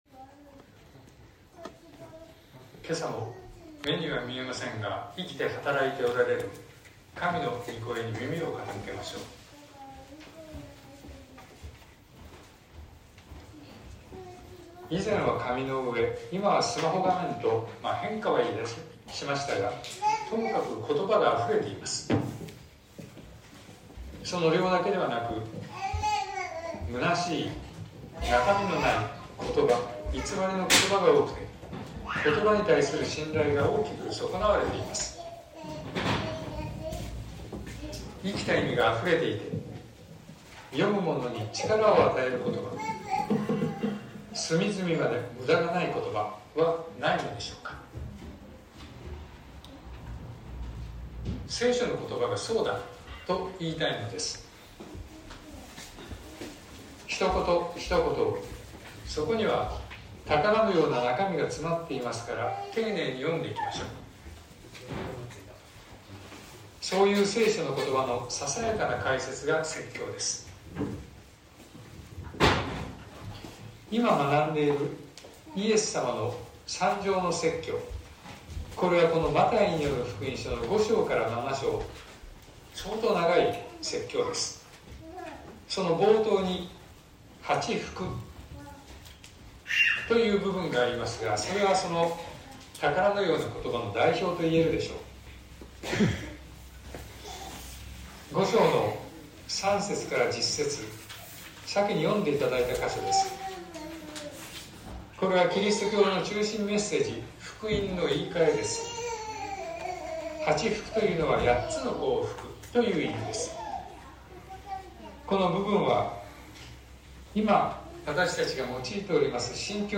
2023年10月22日朝の礼拝「憐みの深い人々は幸いである」東京教会
東京教会。説教アーカイブ。